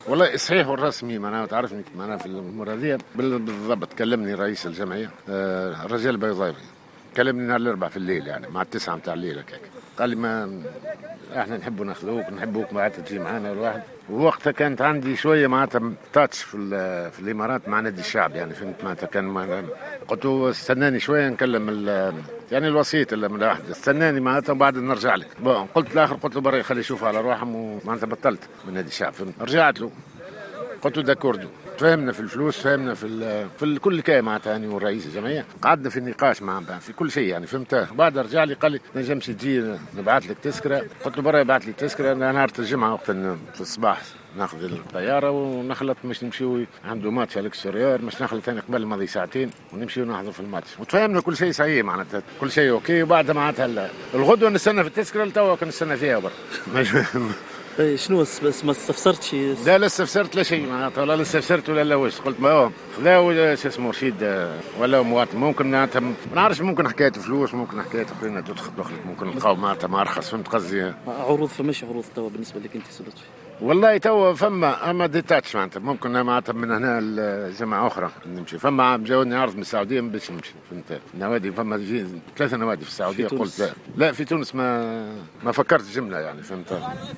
أكد المدرب لطفي البنزرتي في تصريح خص به راديو جوهرة أف أم أنه كان قريبا جدا من...